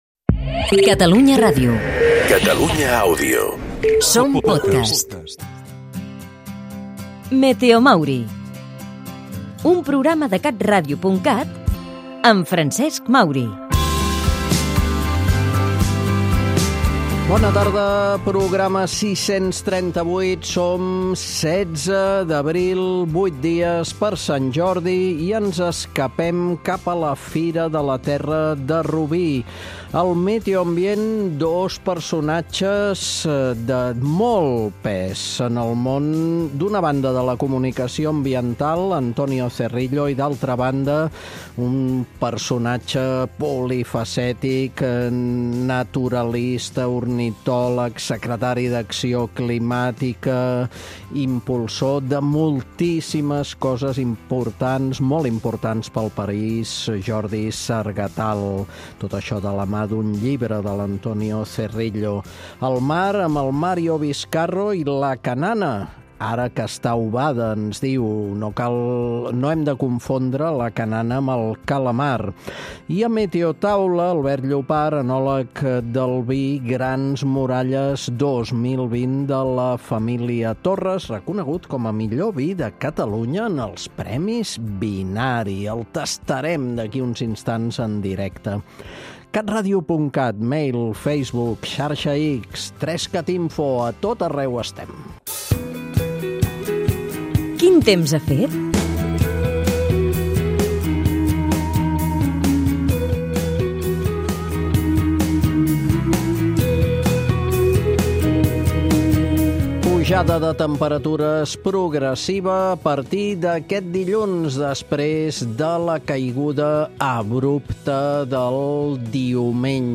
Ens escapem a la Fira de la Terra de Rubí i parlem de cananes, sovint confoses amb el calamar. Acabem tastant un dels grans vins del nostre país, el Grans Muralles 2020.